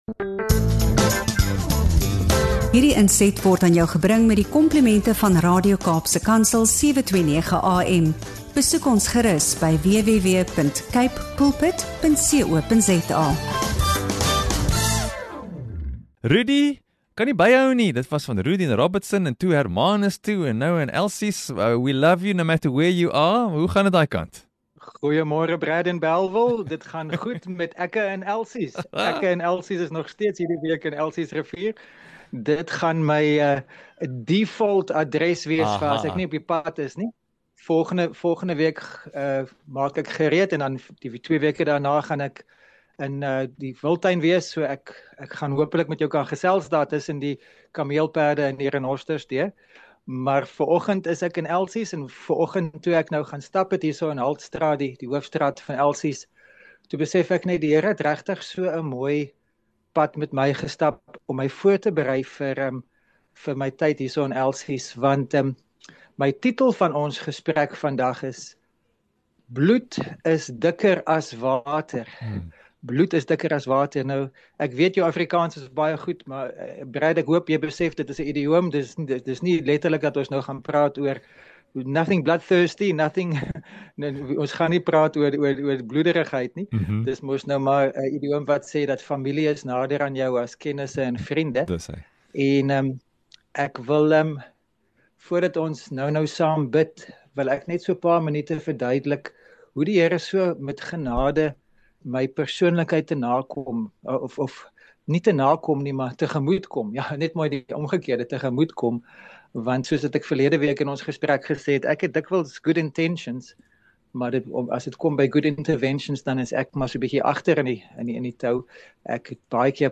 In hierdie inspirerende gesprek vanuit Elsiesrivier